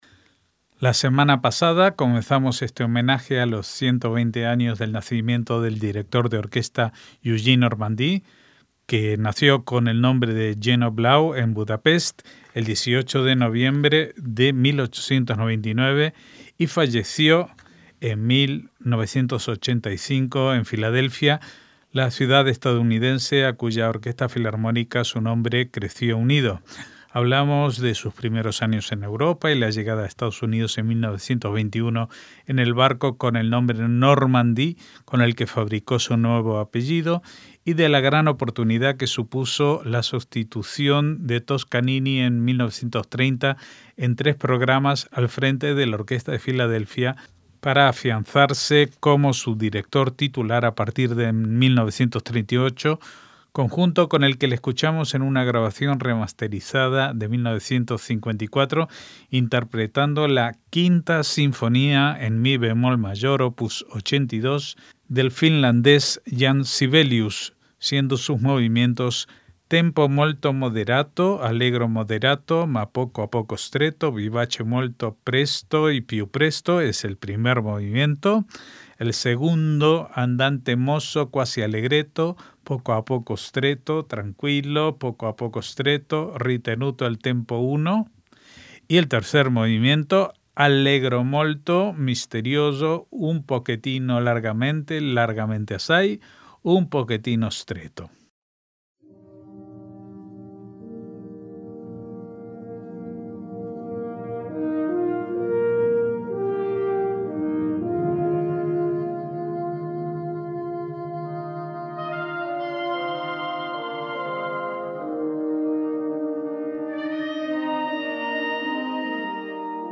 MÚSICA CLÁSICA
en una grabación remasterizada de 1954
en mi bemol mayor, opus 82